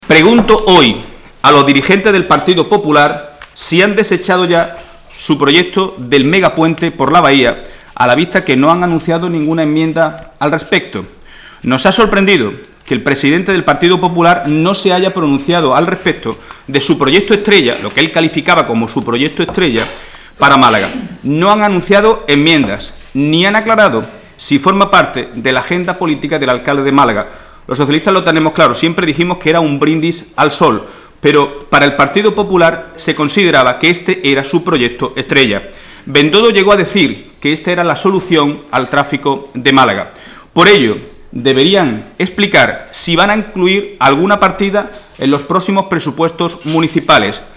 El portavoz socialista, Rafael Fuentes, ha asegurado hoy en rueda de prensa junto con el secretario general del PSOE malagueño, Miguel Ángel Heredia, que entre los fondos FEILS y FEELS del Gobierno central se podrían haber generado en Málaga capital 3.153 puestos de trabajo más de los creados por el equipo de gobierno del PP, en relación a la inversión realizada.